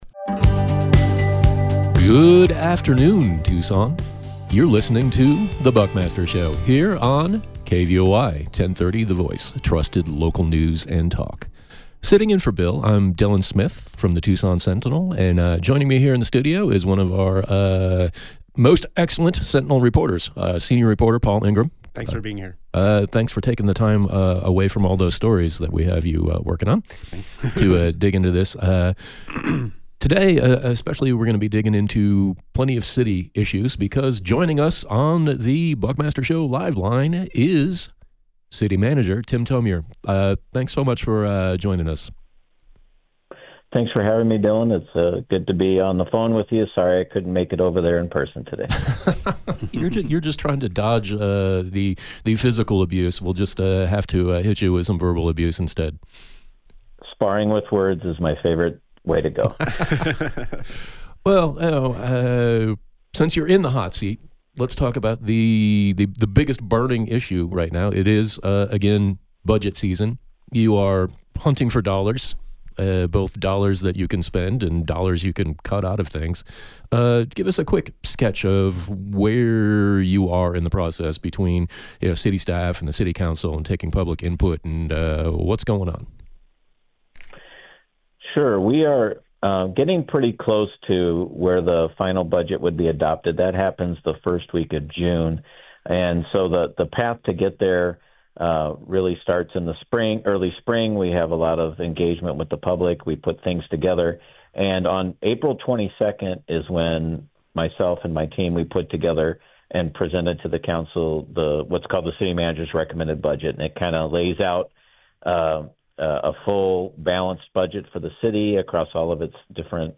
1 Buckmaster Show 5/9/2025: Tucson city budget takes shape; Generational shifts in local government 54:57 Play Pause 1d ago 54:57 Play Pause Play later Play later Lists Like Liked 54:57 Tucson City Manager Tim Thomure discussed the municipal budget process, as well as the challenges of dealing with shifting outside revenues, and new city programs to tackle housing problems and make police/court interactions more efficient.